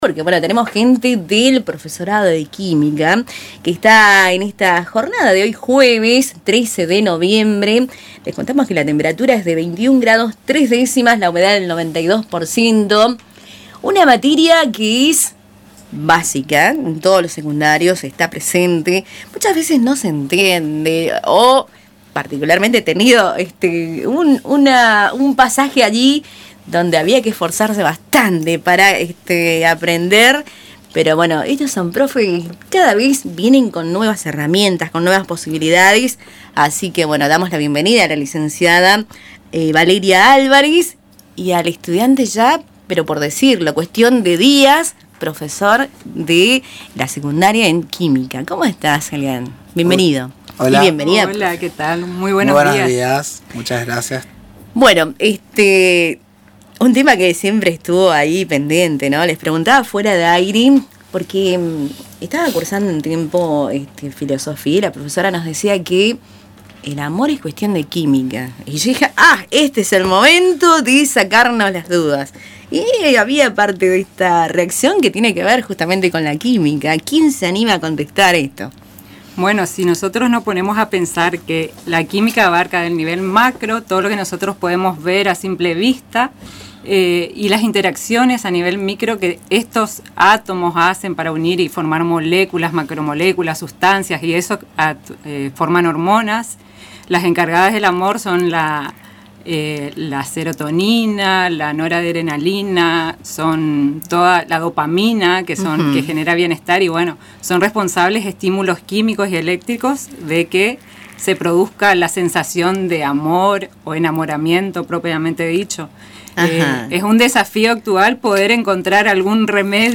en los estudios de Radio Tupambaé